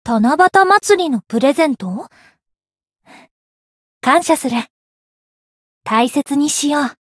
灵魂潮汐-密丝特-七夕（送礼语音）.ogg